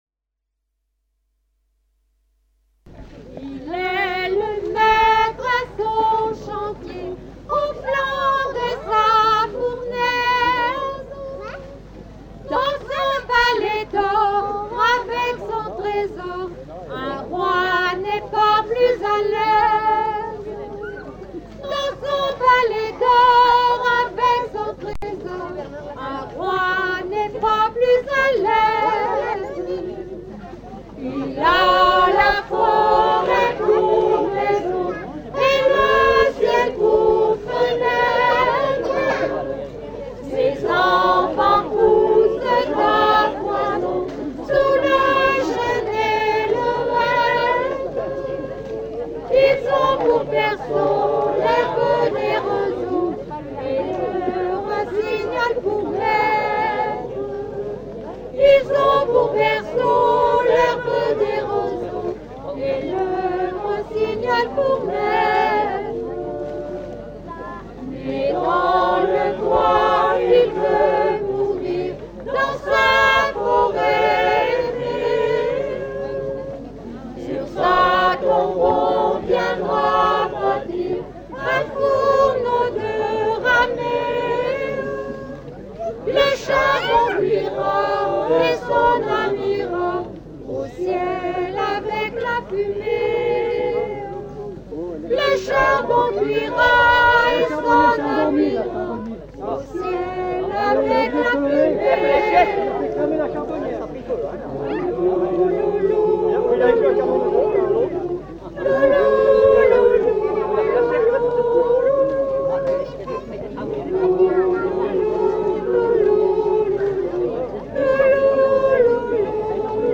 1ère fête de la charbonnière à Rencurel – 12 septembre 1998 –
Chant du charbonnier (groupe folklorique)
ancien charbonnier et expert des édifices
(chorale de Rencurel) Souvenirs d’anciens Jeunes charbonniers